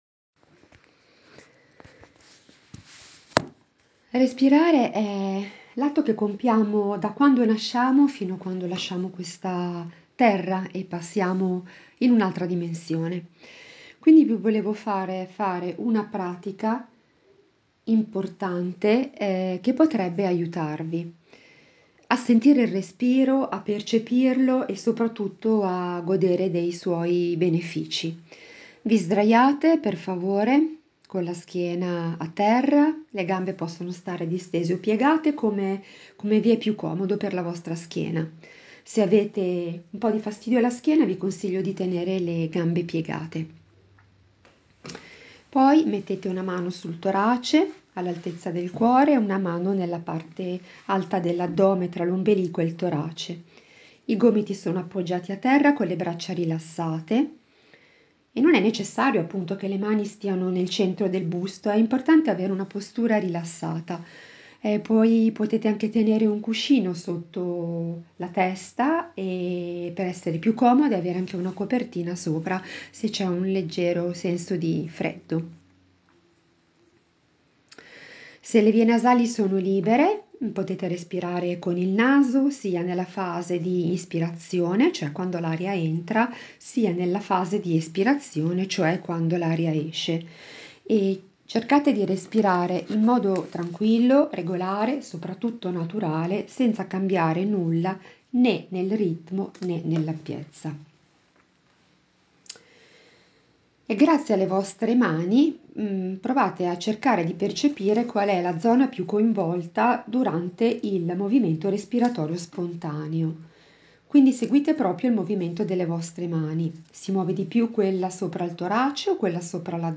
audio-breve-esercizio-sul-respiro.aac